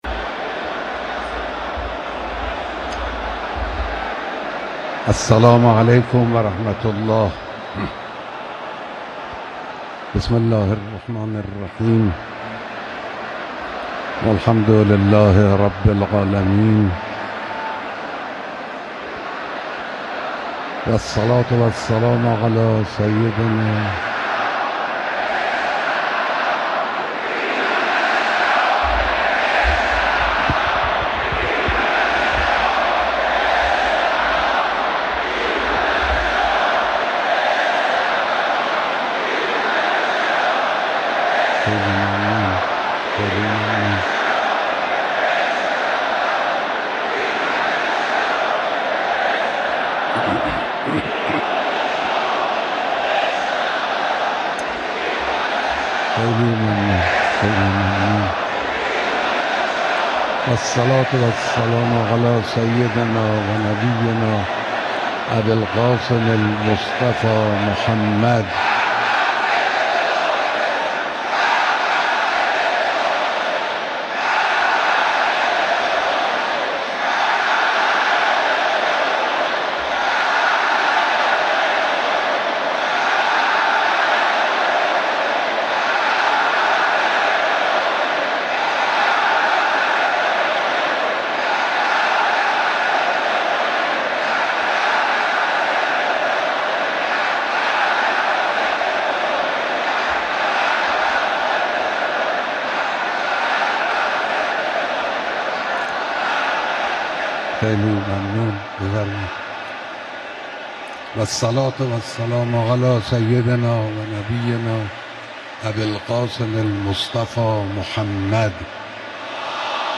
صوت کامل بیانات
حضرت آیت‌الله خامنه‌ای رهبر انقلاب اسلامی عصر امروز (یکشنبه) با حضور در مراسم بیست و هشتمین سالگرد رحلت حضرت امام خمینی (رحمه‌الله) به سخنرانی در جمع هزاران نفری مشتاقان و دلدادگان بنیان‌گذار جمهوری اسلامی ایران پرداختند.